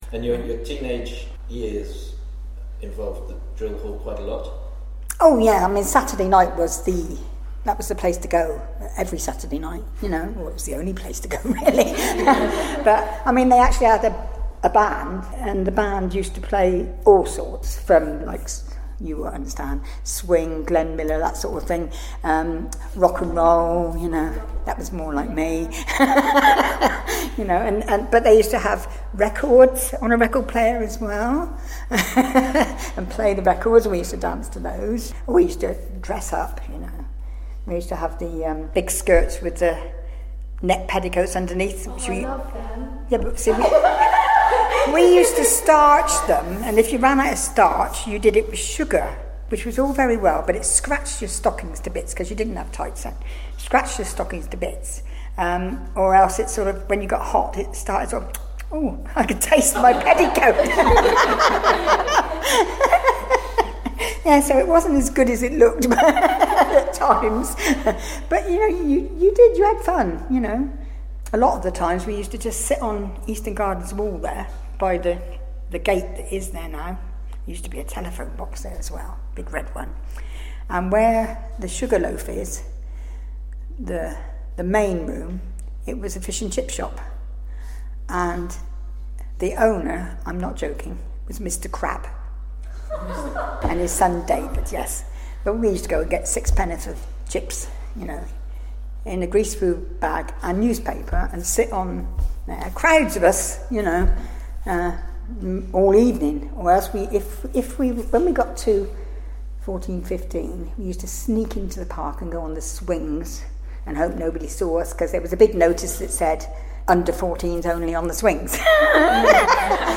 Teenagers ABSOLUTE THEATRE in partnership with learners from the ISLE OF PORTLAND ALDRIDGE COMMUNITY ACADEMY, recorded these anecdotes, memories and experiences of Portland people.